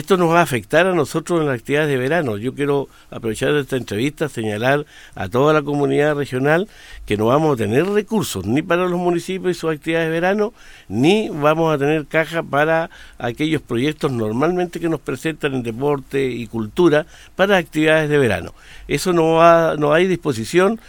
Así lo reconoció en entrevista con Radio Bío Bío, el gobernador Luis Cuvertino, revelando que nuevamente fueron víctimas de un “manotazo” por parte del Ministerio de Hacienda, que otra vez recortó el presupuesto destinado a la billetera regional.